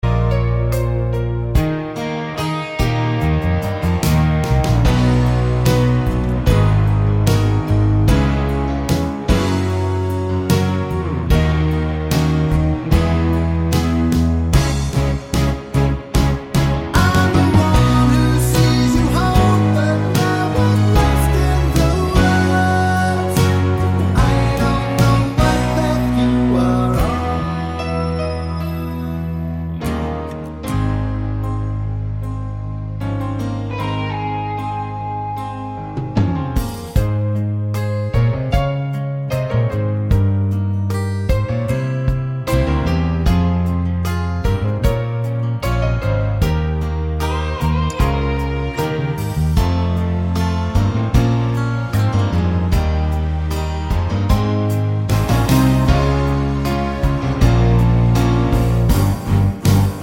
no Backing Vocals Soundtracks 3:01 Buy £1.50